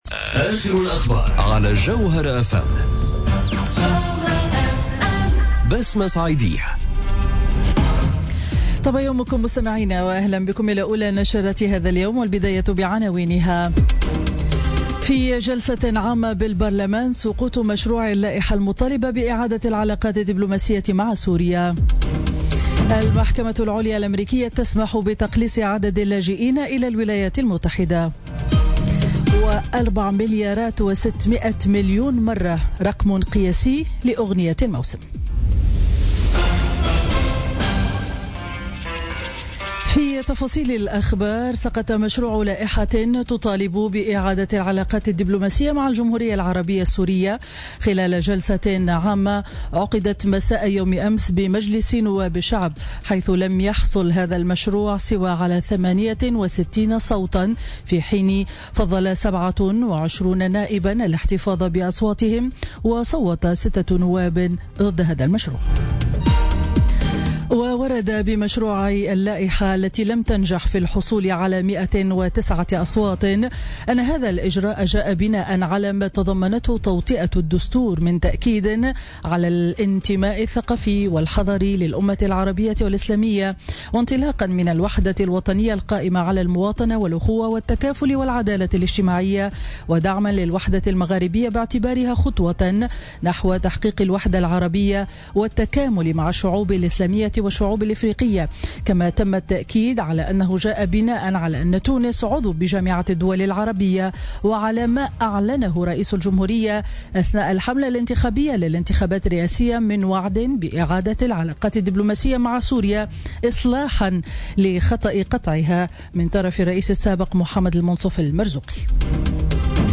نشرة أخبار السابعة صباحا ليوم الخميس 20 جويلية 2017